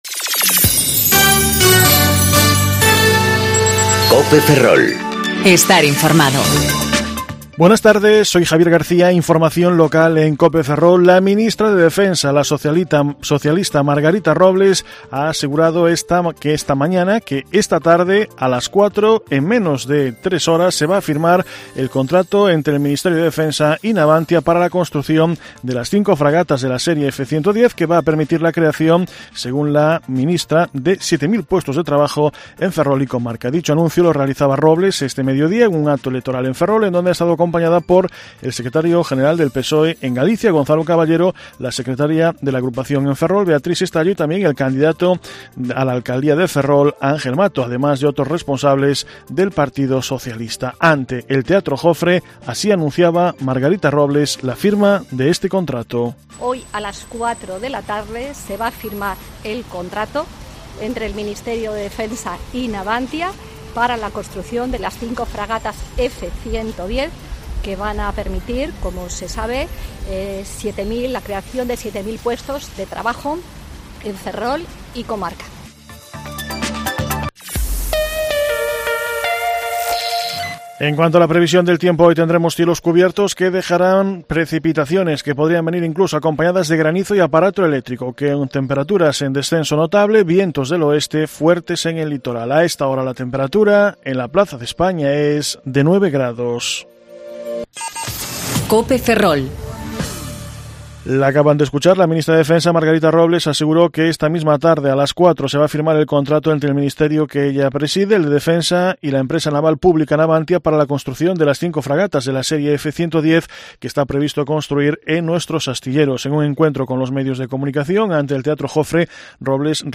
Informativo Mediodía Cope Ferrol 23/04/2019 (De 14,20 a 14,30 horas)